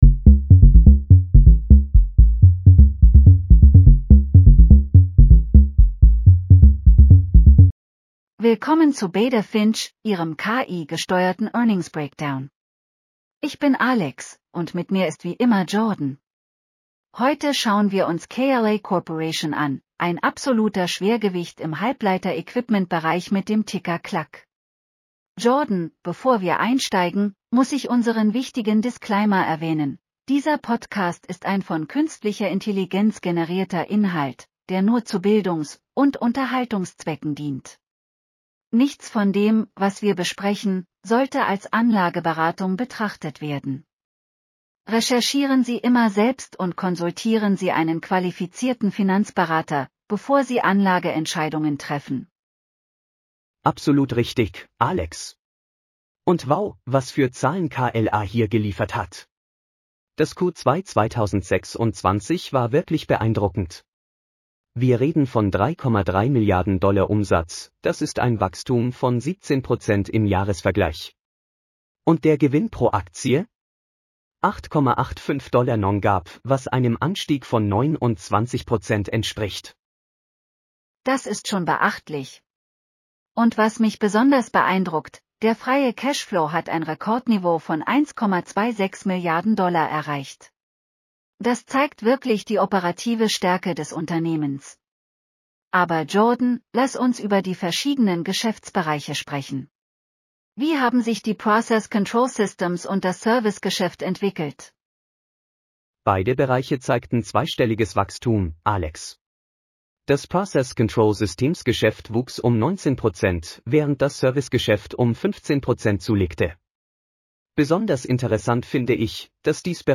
BETA FINCH PODCAST SCRIPT - KLA CORPORATION (KLAC) Q2 2026 EARNINGS